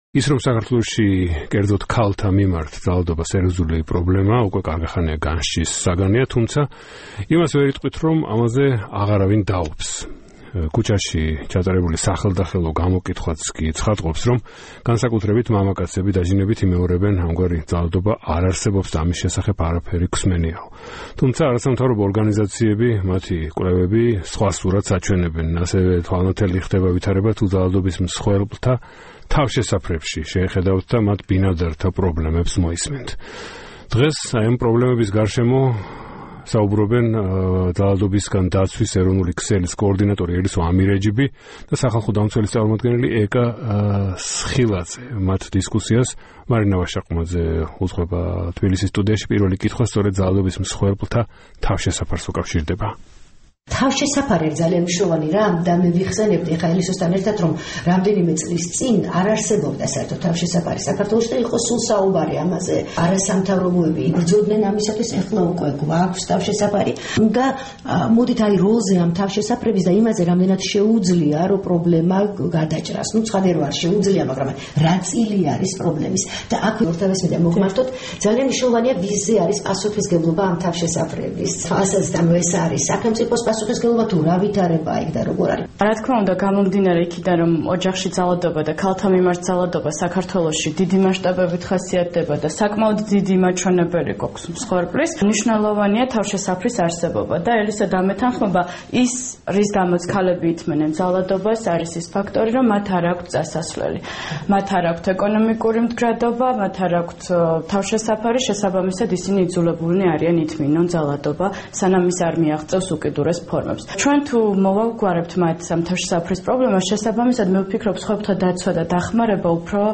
ის, რომ საქართველოში ქალთა მიმართ ძალადობა სერიოზული პრობლემაა, უკვე კარგა ხანია, განსჯის საგანია. თუმცა ვერ ვიტყვით, რომ ამაზე აღარავინ დავობს. ქუჩაში ჩატარებული სახელდახელო გამოკითხვაც კი ცხადყოფს, რომ განსაკუთრებით მამაკაცები დაჟინებით იმეორებენ, ამგვარი ძალადობა არ არსებობს და ამის შესახებ არაფერი გვსმენიაო.